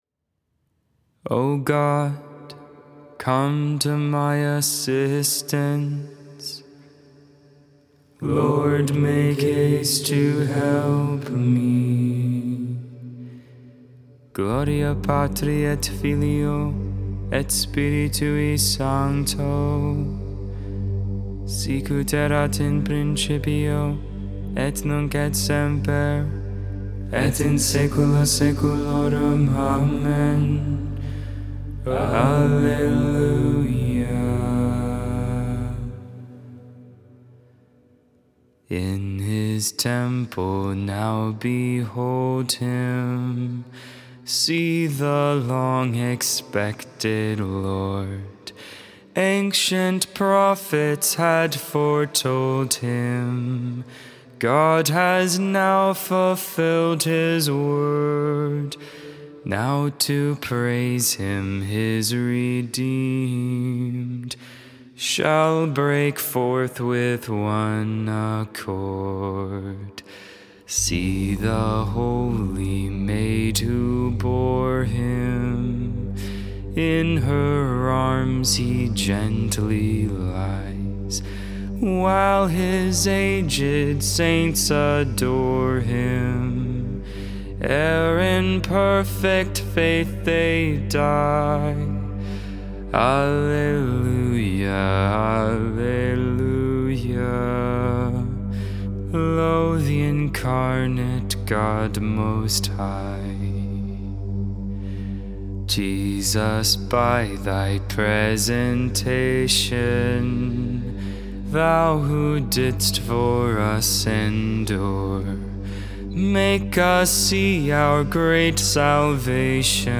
2.2.22 Candlemas Lauds, Wednesday Morning Prayer